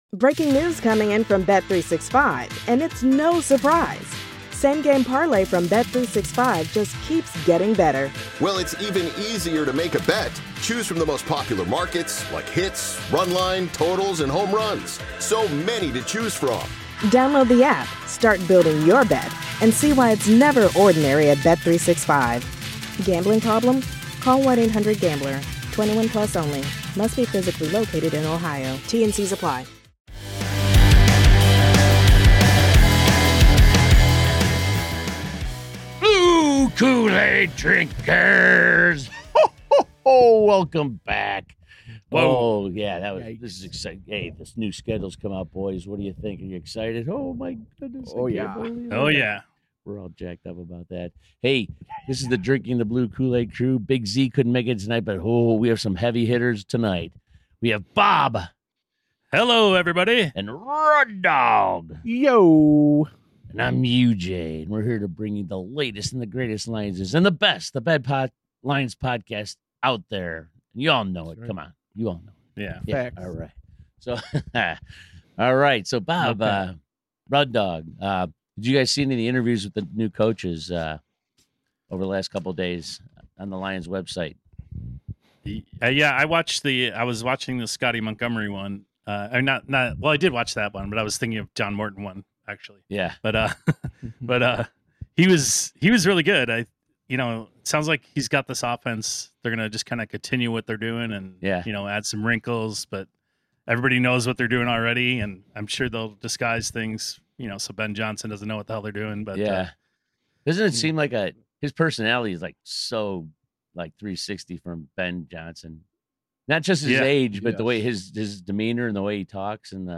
A few Lions fans discussing our favorite team as they prepare for greatness.